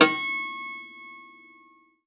53w-pno09-C4.wav